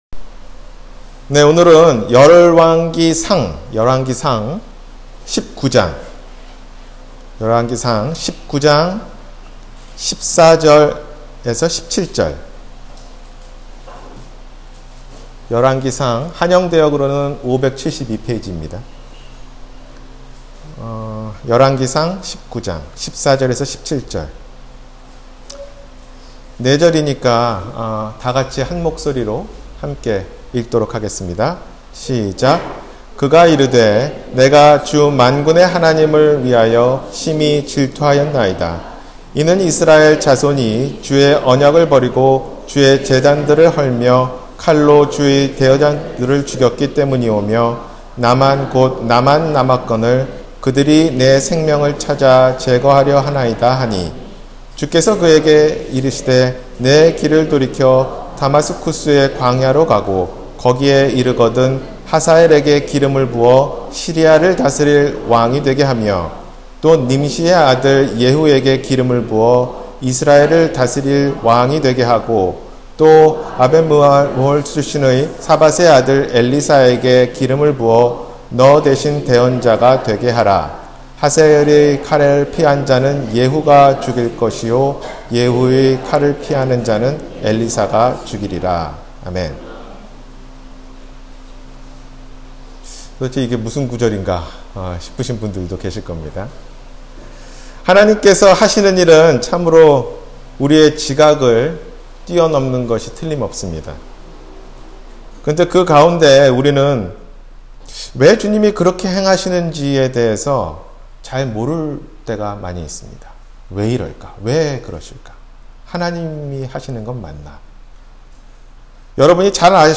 하나님 왜 가만히 계십니까? – 주일설교